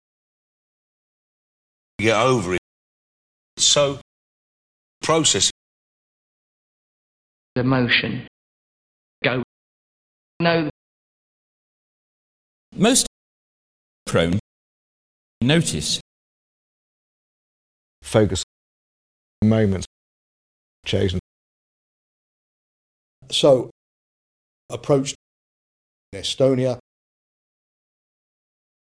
Example 3: The London or home counties SBE GOAT vowel: [aʊ]-[ao]
Three examples each by five speakers, in the same order as Example 1: